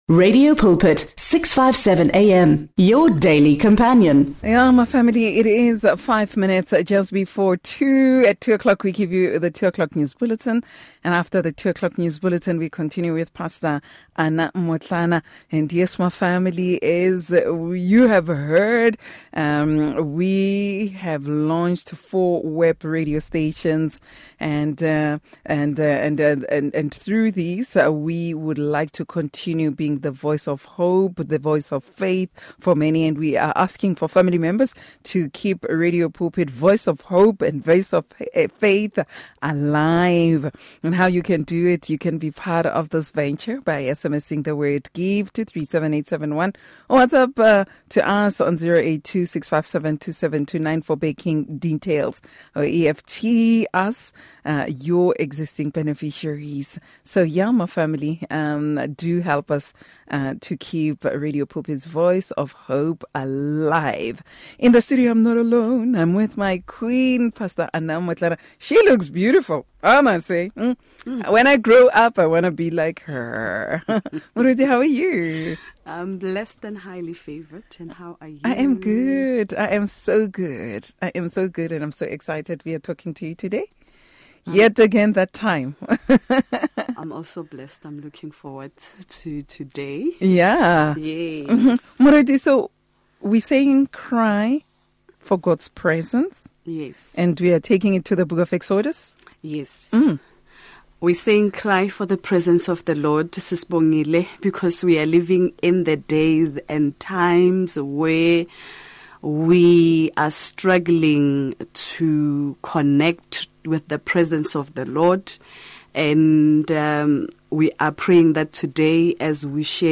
is welcomed back in the studio